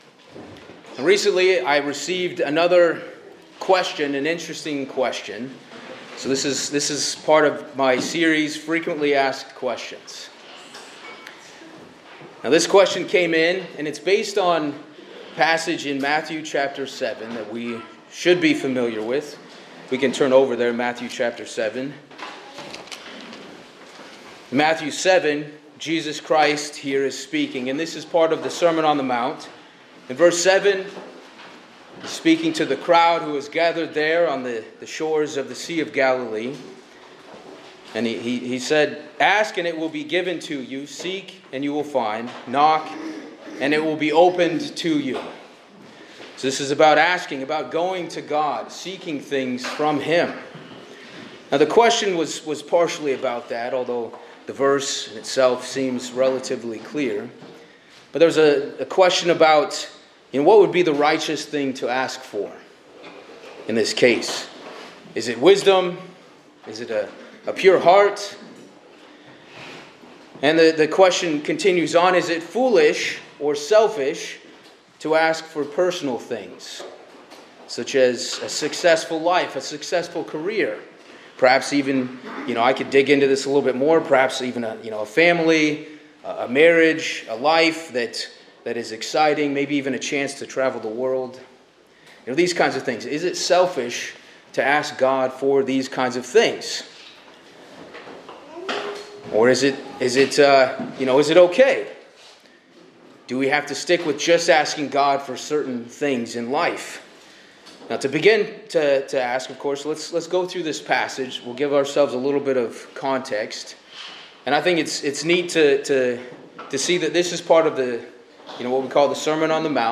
The sermon begins by referencing Matthew 7:7-12, where Jesus encourages believers to ask, seek, and knock, assuring them that God will respond positively to their requests. The speaker addresses questions regarding what constitutes righteous requests, exploring whether it is selfish to ask for personal desires. The speaker emphasizes the importance of context and the heart's intention behind the requests made to God.